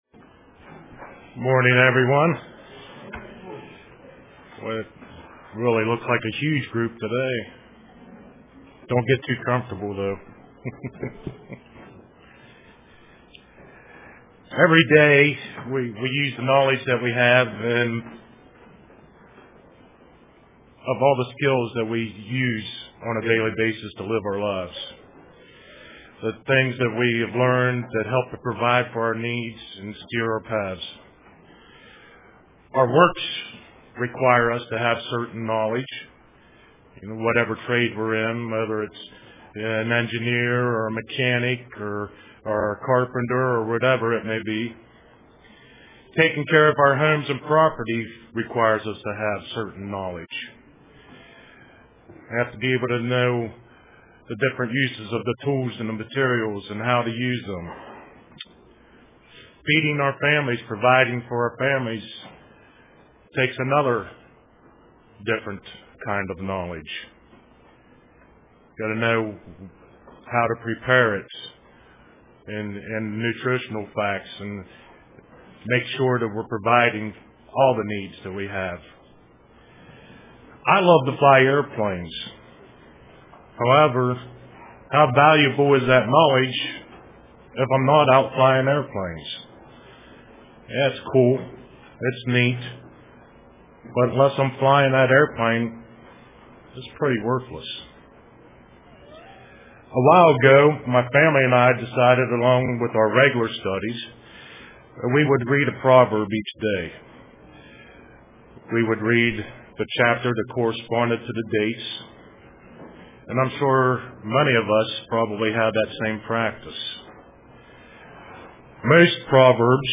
Print True Knowledge UCG Sermon Studying the bible?